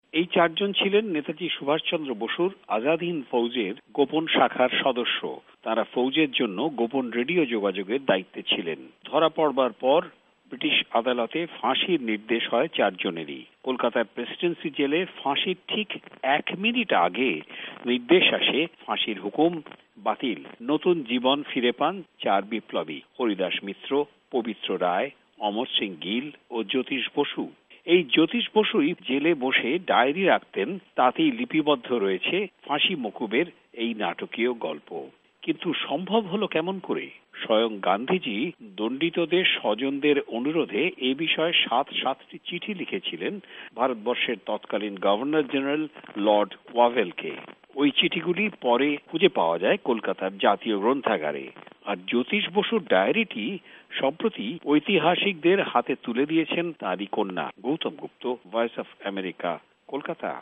ভয়েস অফ এ্যামেরিকার কলকাতা সংবাদদাতাদের রিপোর্ট